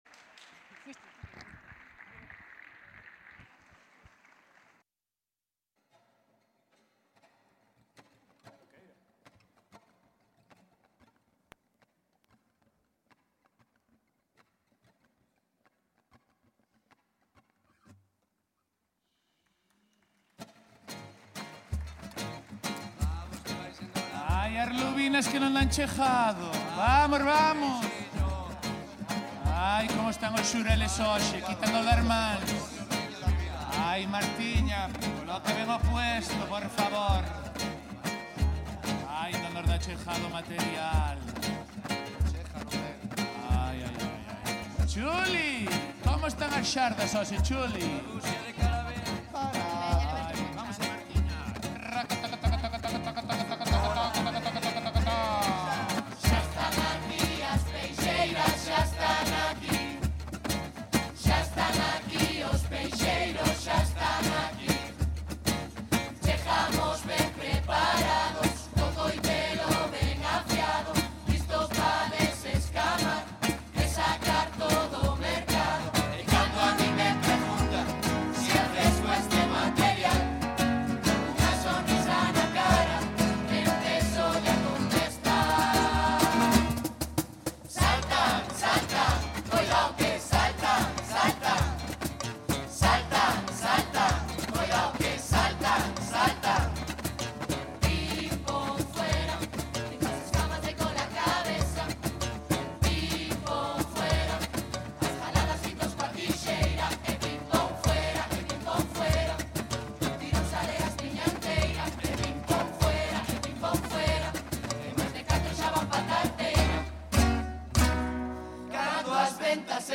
Leña Verde - Concurso de Murgas de Pontevedra 2025